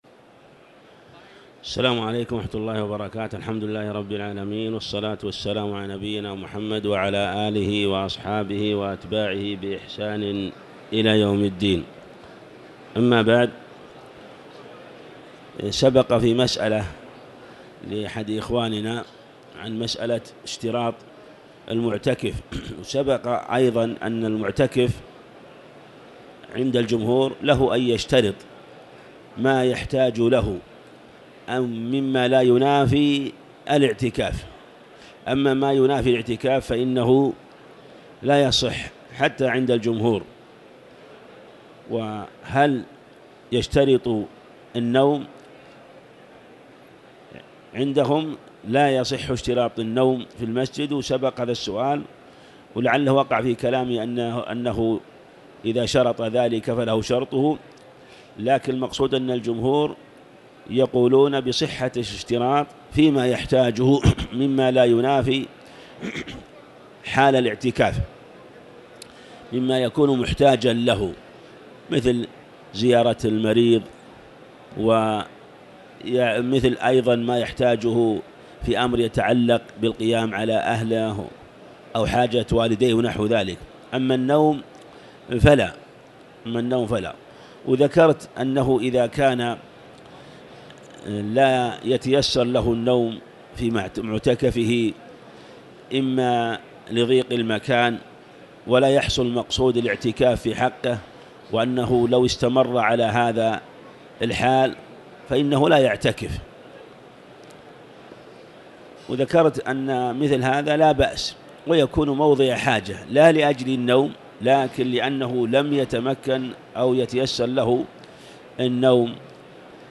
تاريخ النشر ٢٣ رمضان ١٤٤٠ هـ المكان: المسجد الحرام الشيخ